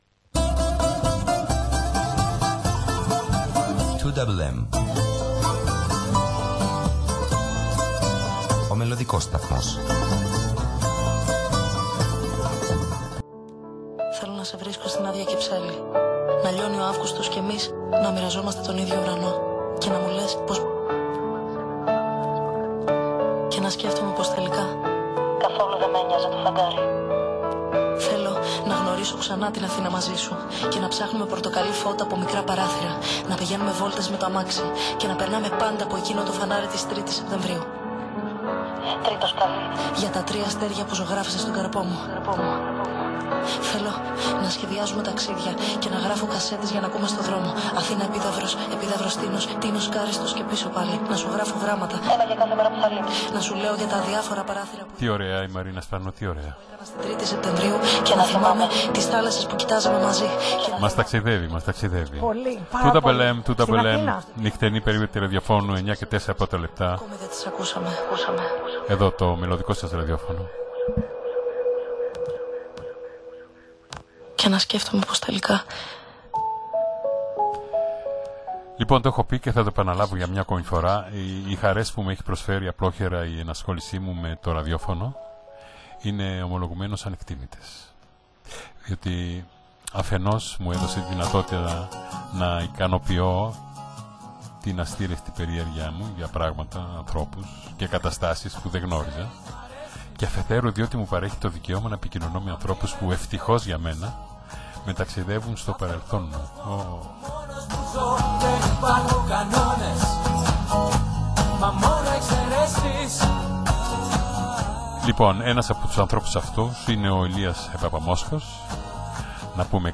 σε μία εφ’όλης της ύλης ζωντανή ραδιοφωνική συνέντευξη